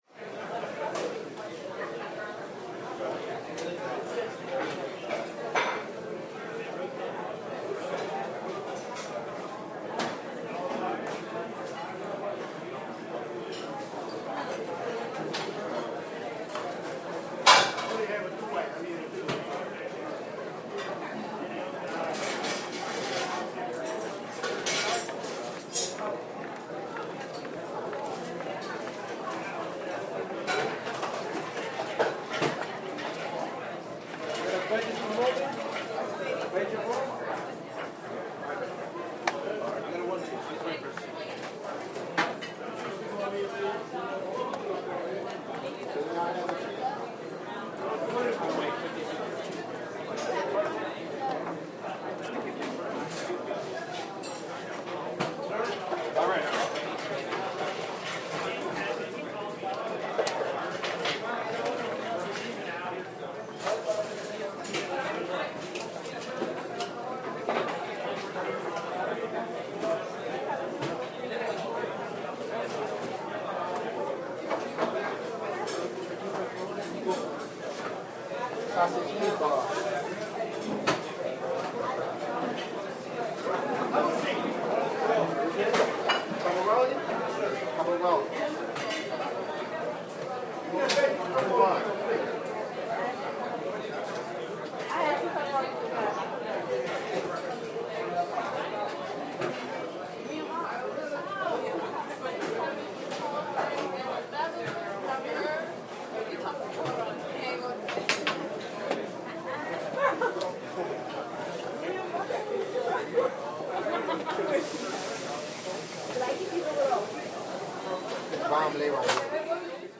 Атмосферный звук пиццерии